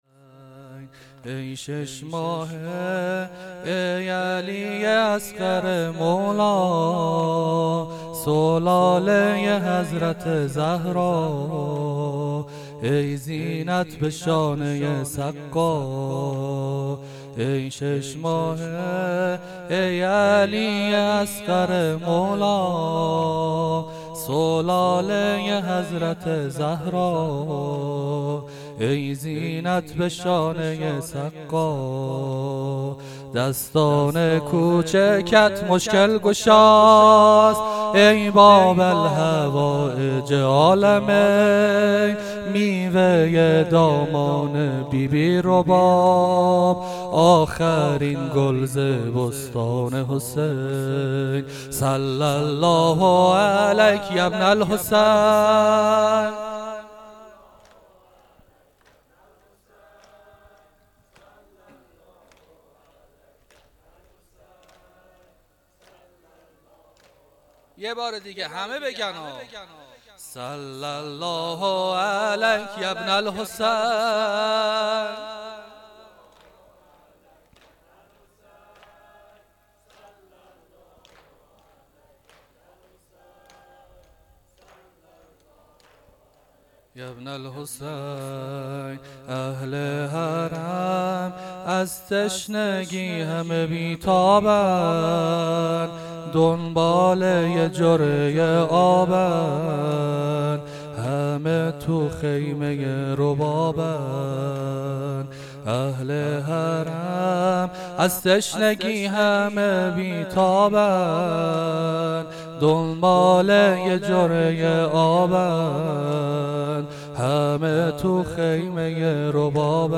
مراسم عزاداری دهه اول محرم الحرام 1399 - مسجد صاحب الزمان (عج) هرمزآباد